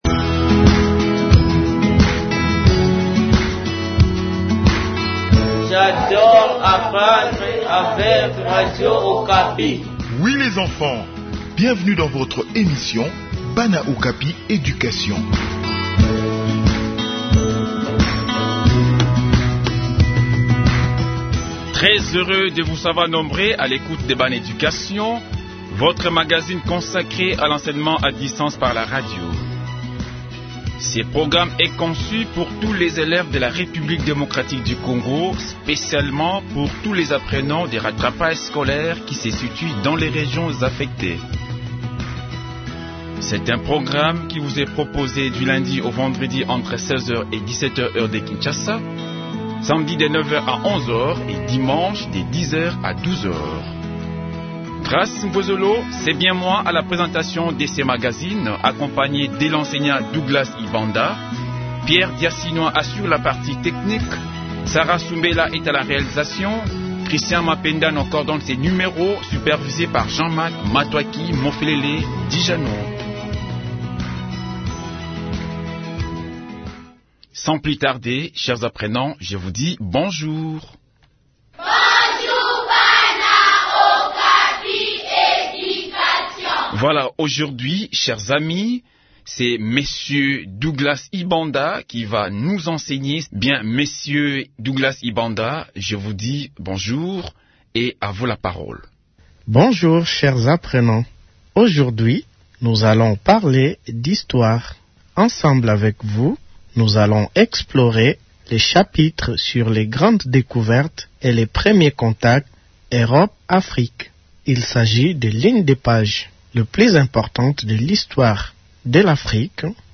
Enseignement à distance : leçon d’Histoire sur les grandes découvertes